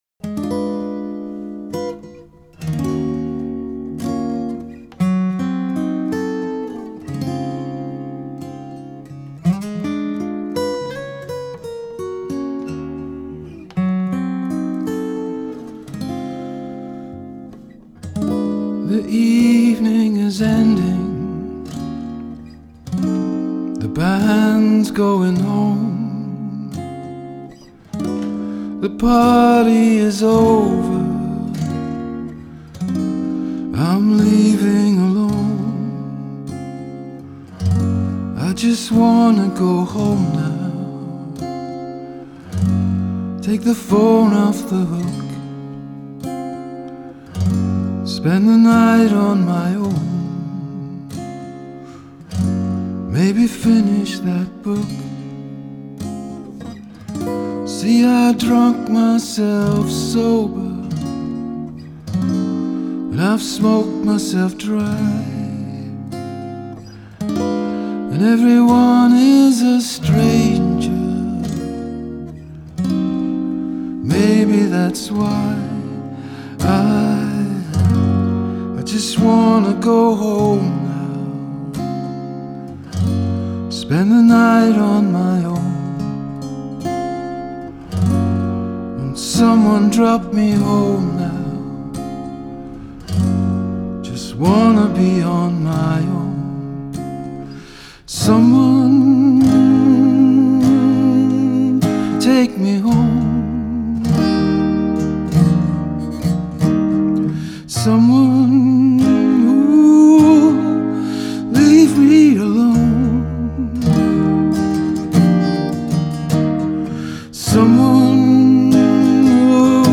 poignant contemplation of the day to day.
Singer-songwriter